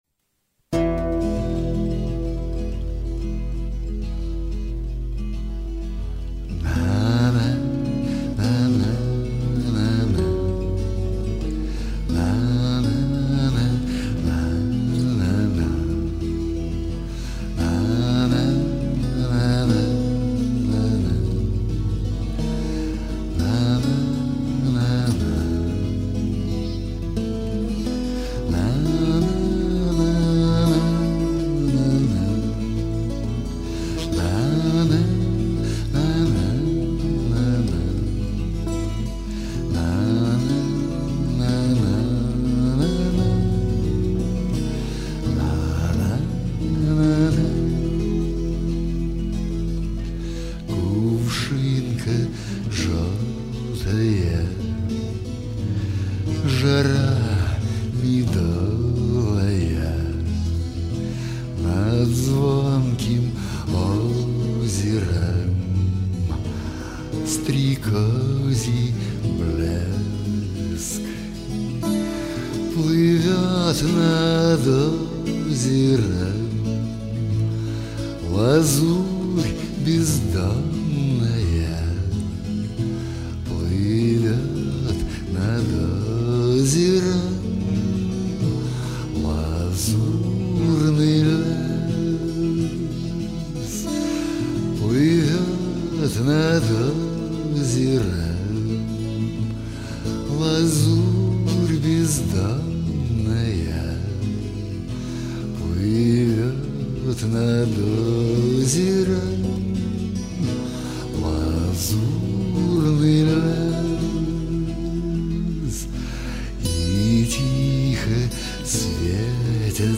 Шансон
И песня - такая покойная, умиротворяющая!